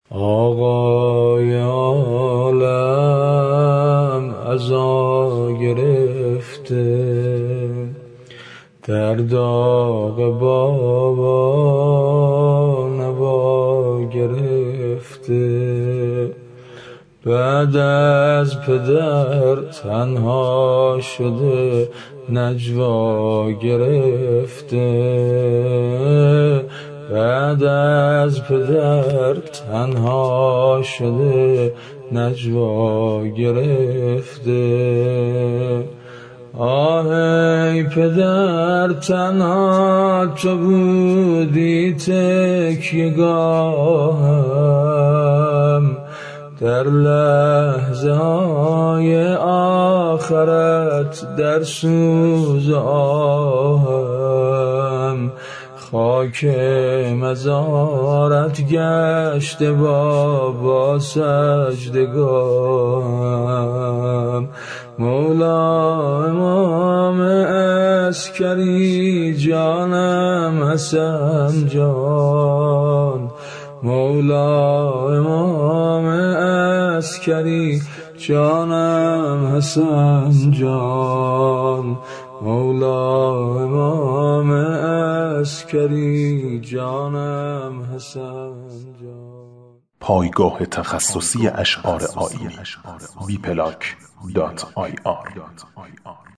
شعر نوحه شهادت امام حسن عسکری (ع) -(آقای عالم ، عزا گرفته)